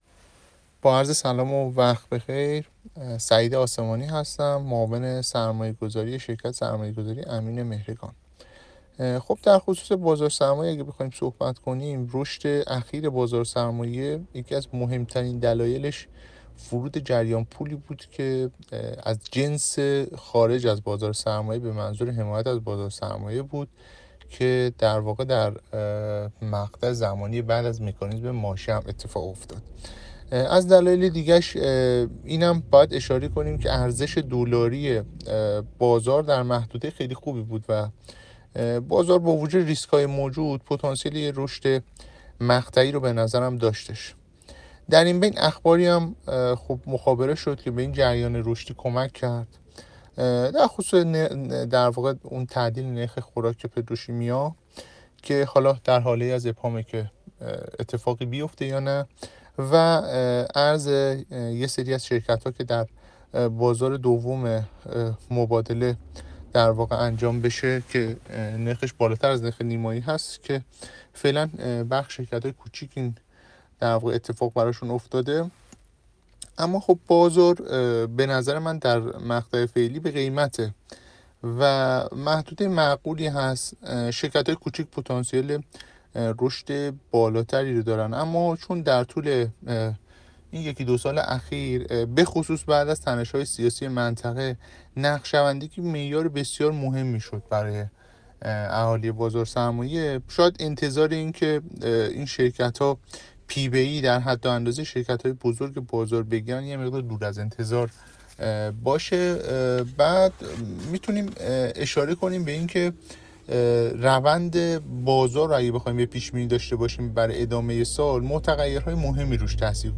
در گفت‌و‌گو با بورس نیوز